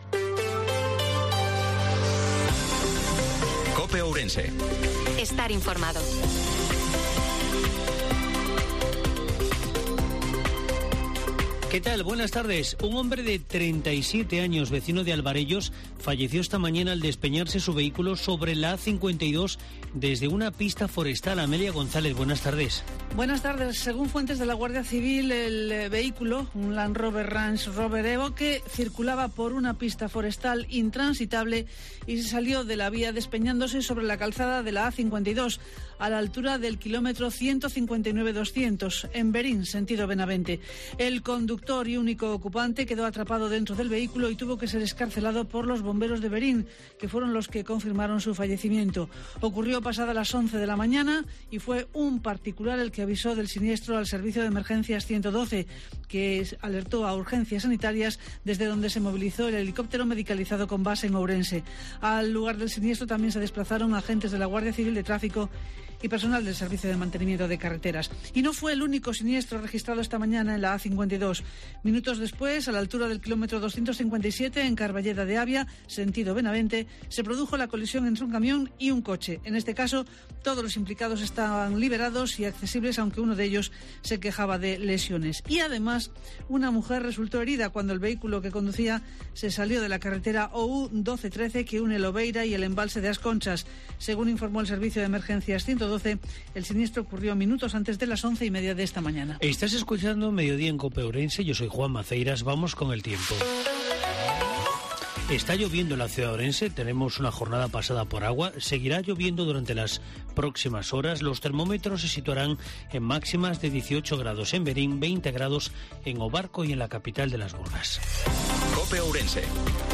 INFORMATIVO MEDIODIA COPE OURENSE-25/10/2022